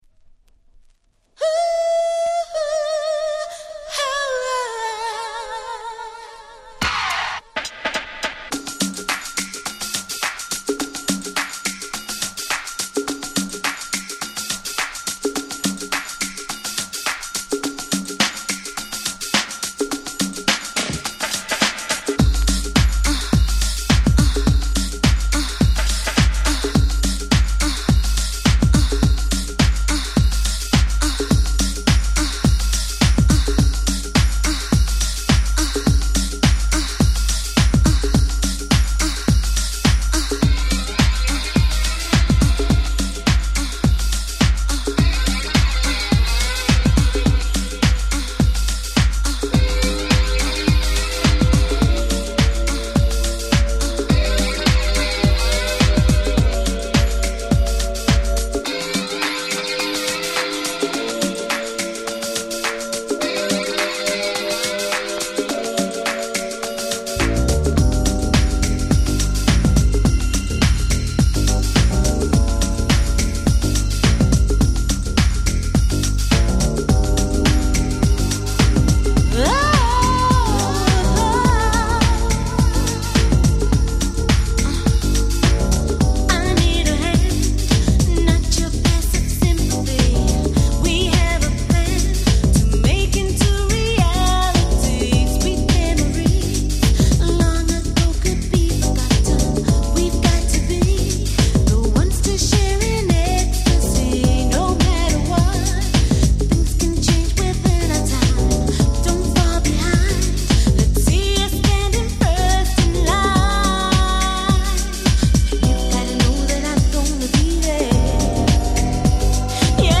UK Soul Classics !!